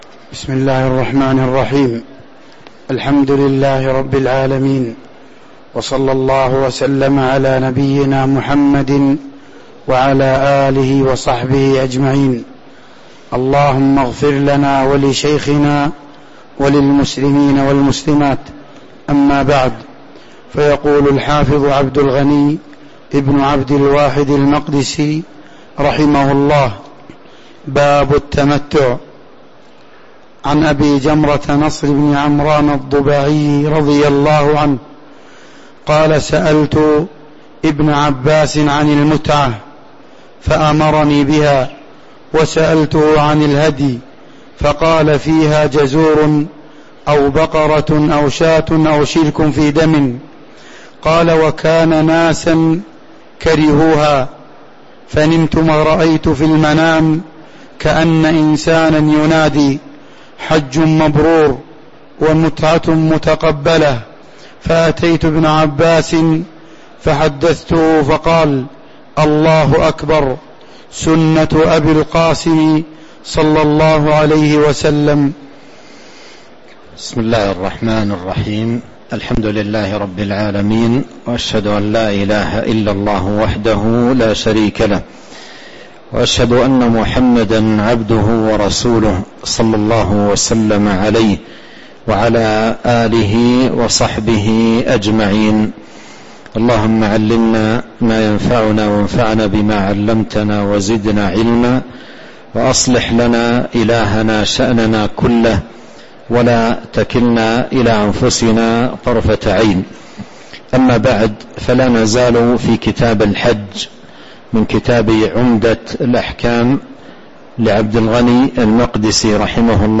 تاريخ النشر ١٥ جمادى الآخرة ١٤٤٤ هـ المكان: المسجد النبوي الشيخ: فضيلة الشيخ عبد الرزاق بن عبد المحسن البدر فضيلة الشيخ عبد الرزاق بن عبد المحسن البدر باب التمتع (05)